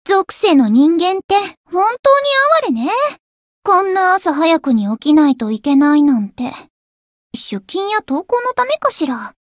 灵魂潮汐-敖绫-问候-晴天上午-初识.ogg